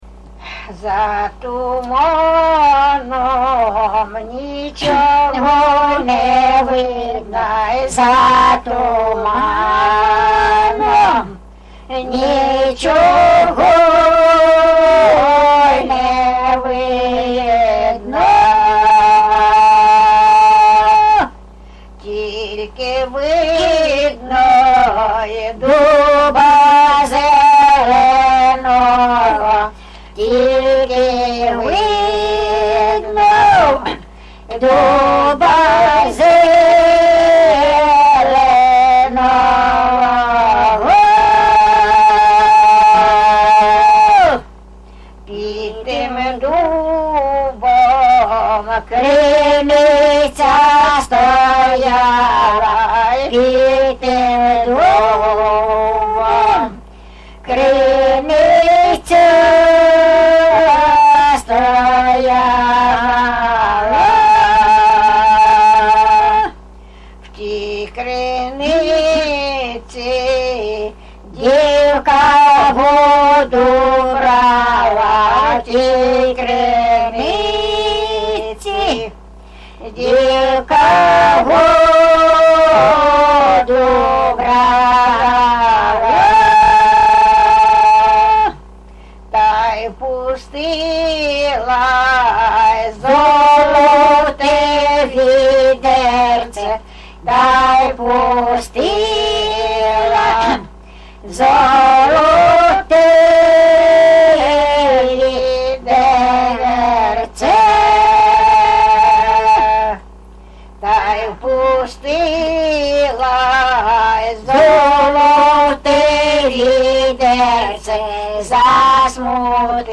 ЖанрПісні з особистого та родинного життя
Місце записус. Закітне, Краснолиманський (Краматорський) район, Донецька обл., Україна, Слобожанщина